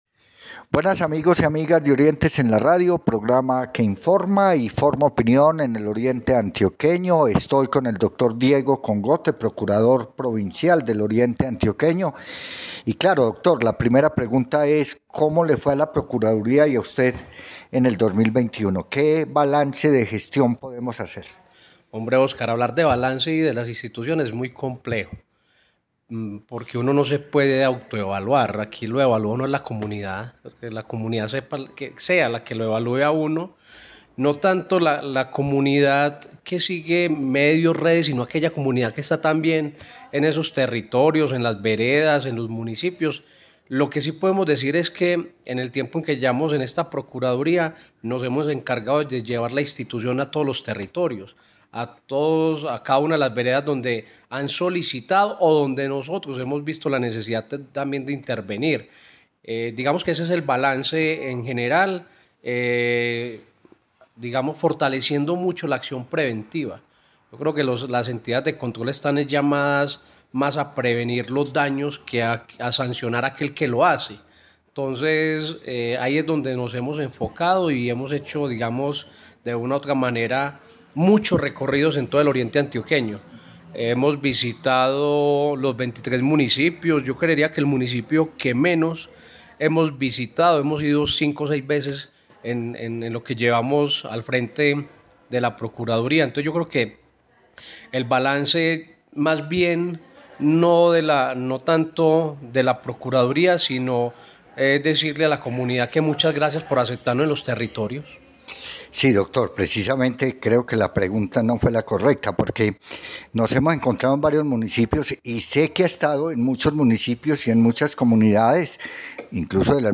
Entrevista con el procurador provincial:
Entrevista-con-el-procurador-parte-1.mp3